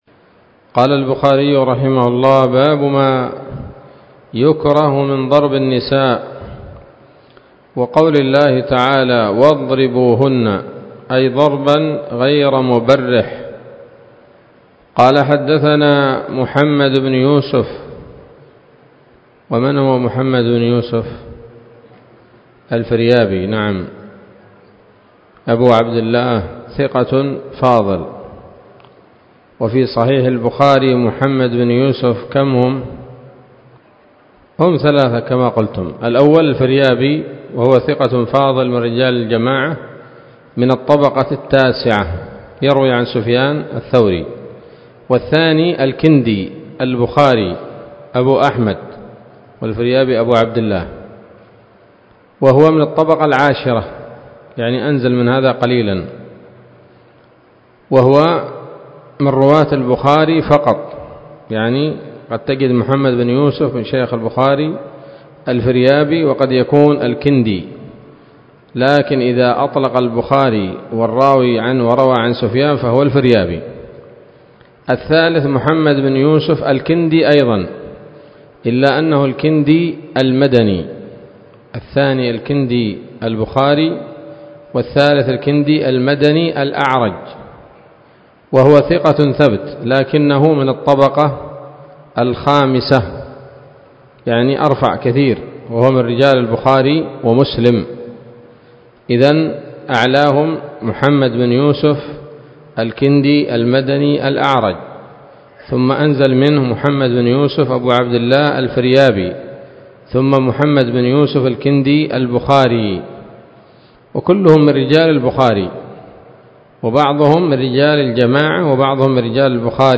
الدرس السادس والسبعون من كتاب النكاح من صحيح الإمام البخاري